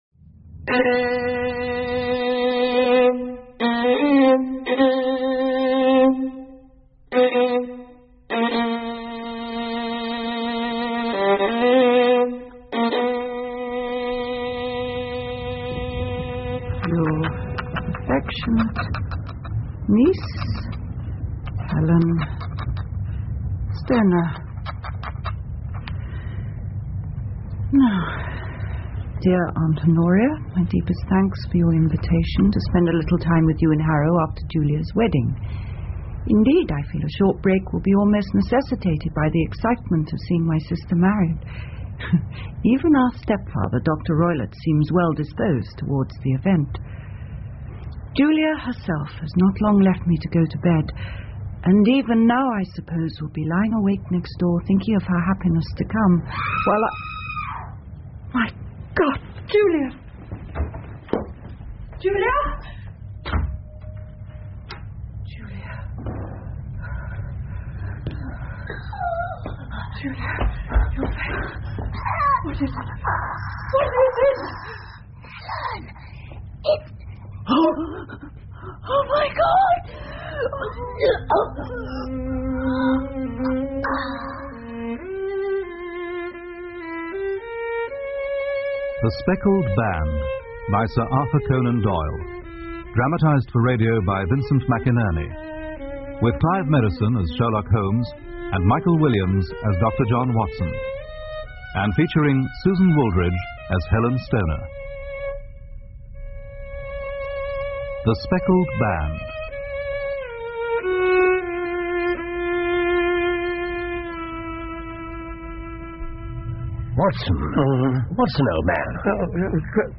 福尔摩斯广播剧 The Speckled Band 1 听力文件下载—在线英语听力室